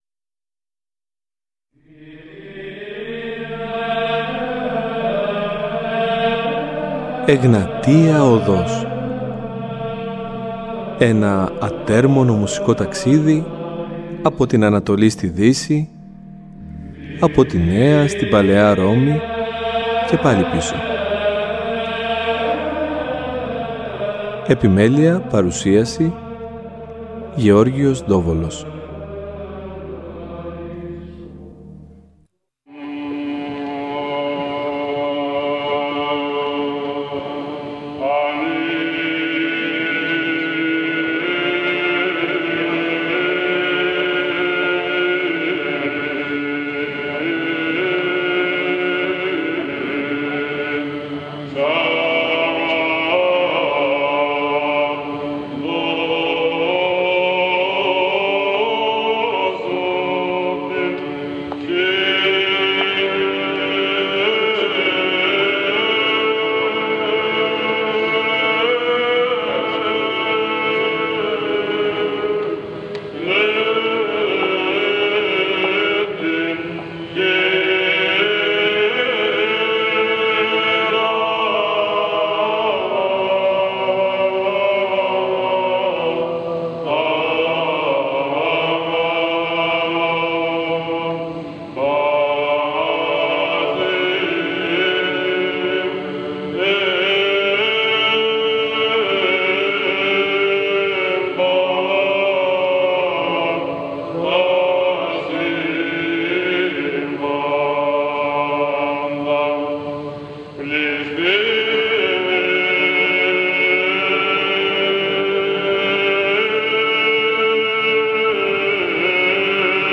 Παράλληλα θα έχουμε τη χαρά να ακούσουμε σπάνιες ηχογραφήσεις οι οποίες πραγματοποιήθηκαν το έτος 1960 από το ίδρυμα της ελληνικής ραδιοφωνίας